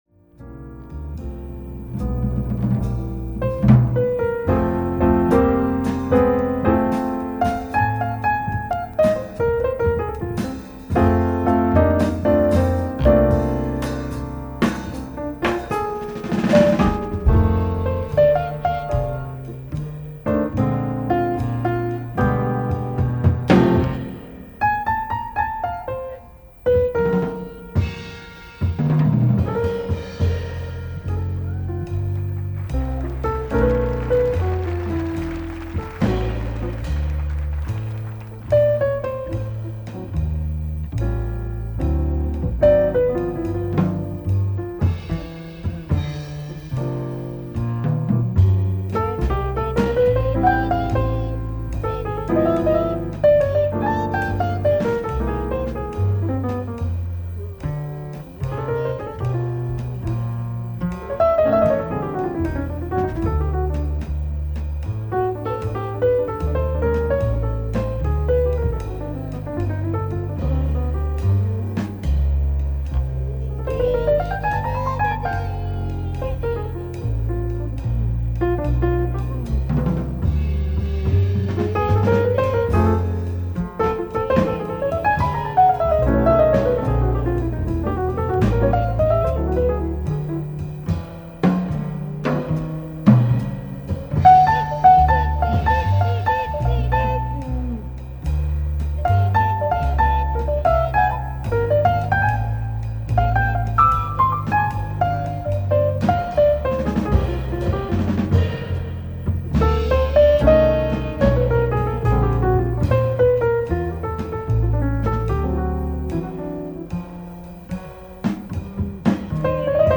ライブ・アット・アンティーブ・ジャズ、ジュアン・レ・パン、フランス 07/25/1992
※試聴用に実際より音質を落としています。